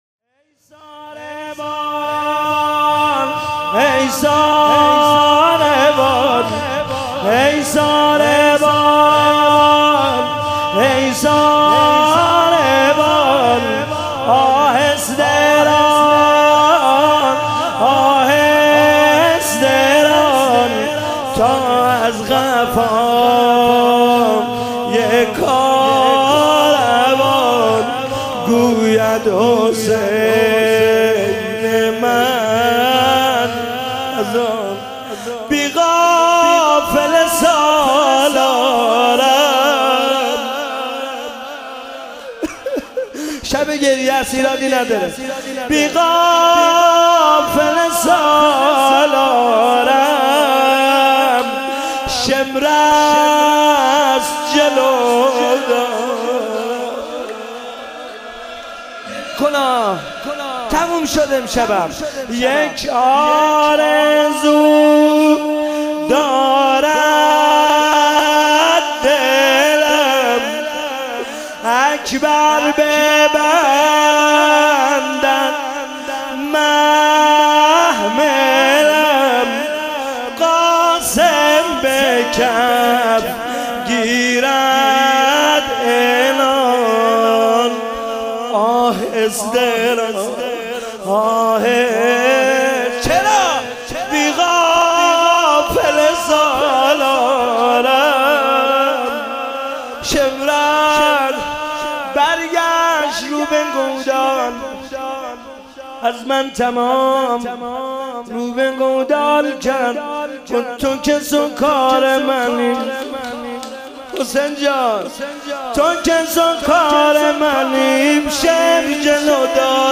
شام غریبان محرم96 - شور - ای ساربان